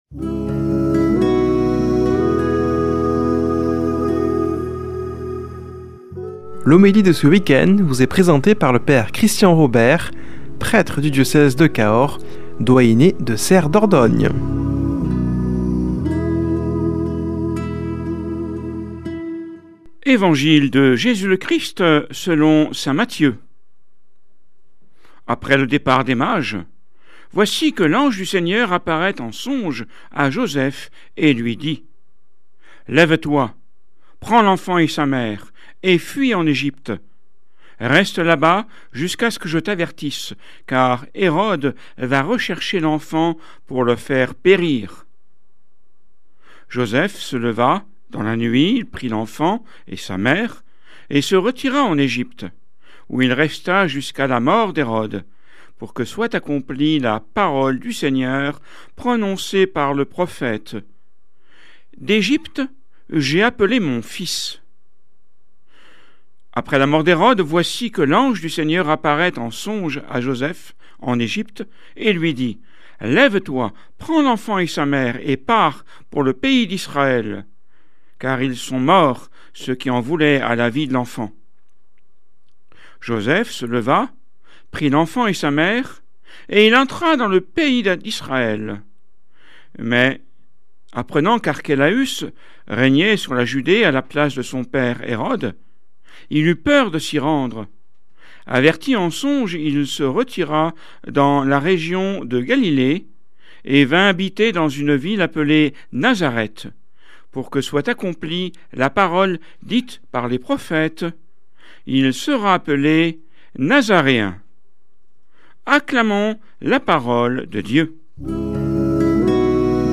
Homélie du 27 déc.